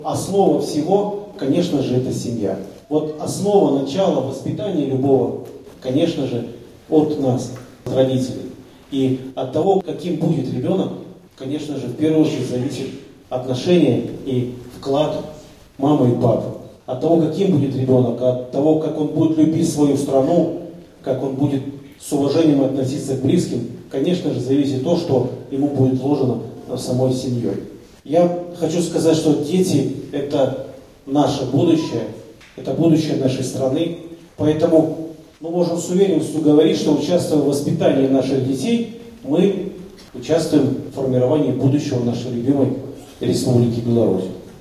В Барановичах масштабно прошел семейный форум «Крепкая семья — процветающая страна!».
Между гостями и аудиторией состоялся доверительный живой разговор о самом сокровенном: что делает семью крепостью, а страну – единой и процветающей.